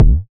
Bassy Kickdrum F Key 163.wav
Royality free kickdrum sound tuned to the F note. Loudest frequency: 123Hz
bassy-kickdrum-f-key-163-IFc.mp3